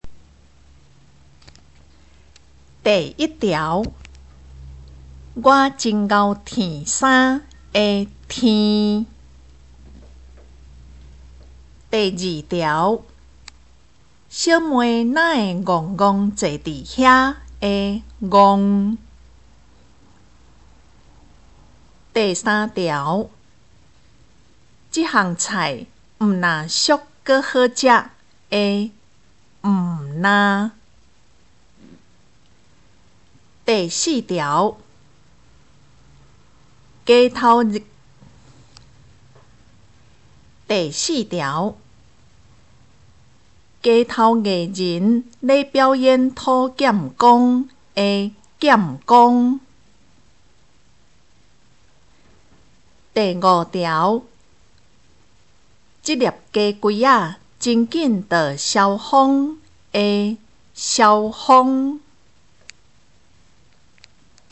【國中閩南語2】每課評量(1)聽力測驗mp3